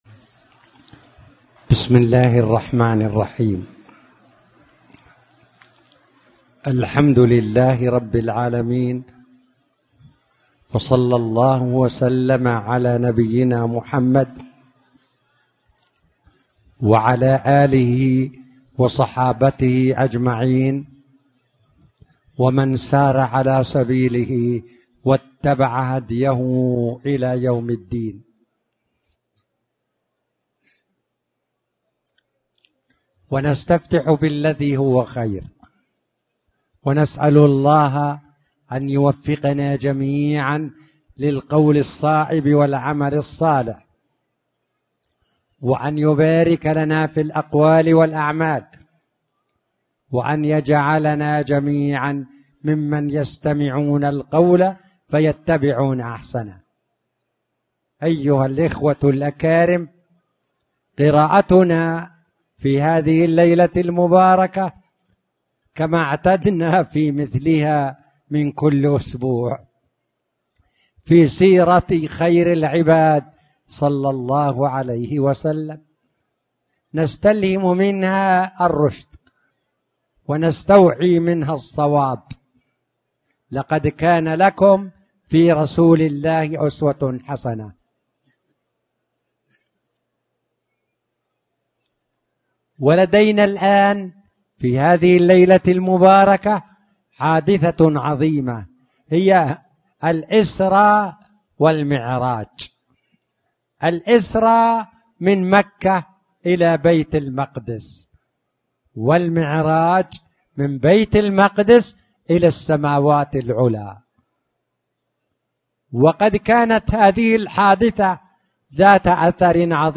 تاريخ النشر ٢٠ صفر ١٤٣٩ هـ المكان: المسجد الحرام الشيخ